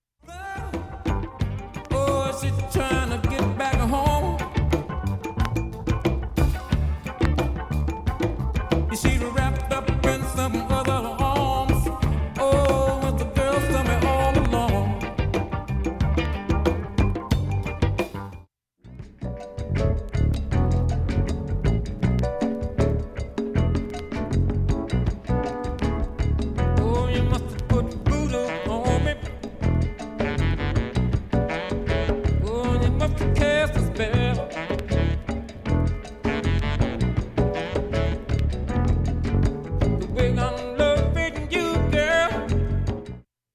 音質良好全曲試聴済み。